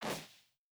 Jump Step Snow B.wav